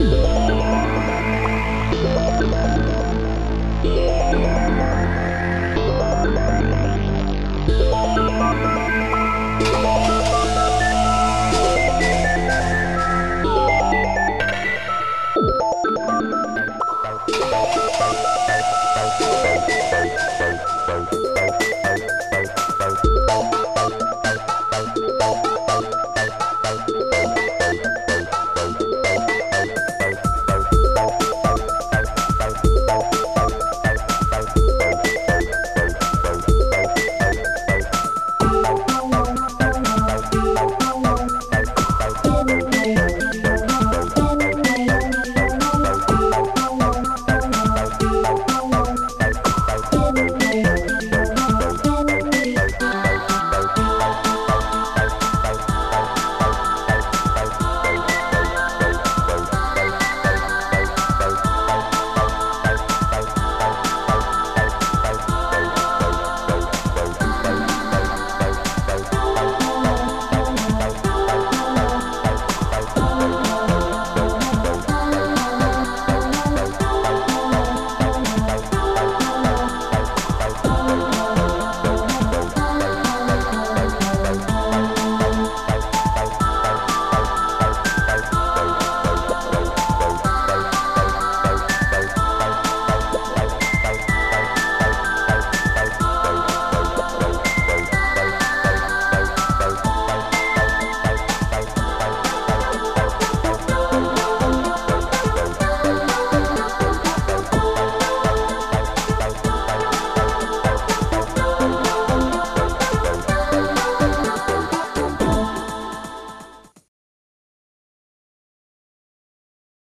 Protracker Module
Type Protracker and family